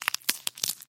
Хруст чеснока под ножом